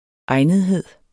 Udtale [ ˈɑjnəðˌheðˀ ]